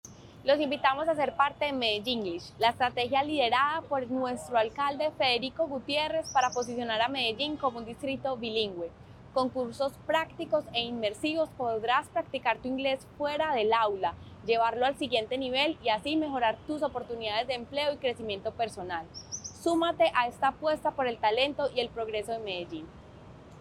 Declaraciones de la secretaria de Desarrollo Económico, María Fernanda Galeano Rojo
Declaraciones-de-la-secretaria-de-Desarrollo-Economico-Maria-Fernanda-Galeano-Rojo.mp3